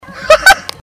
Laugh 2